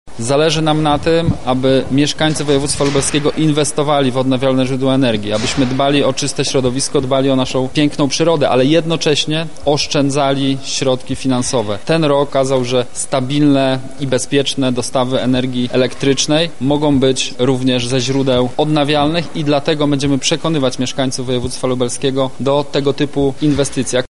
• mówi Michał Mulawa, wicemarszałek województwa lubelskiego.